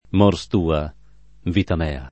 [lat. m 0 r S t 2 a, v & ta m $ a ]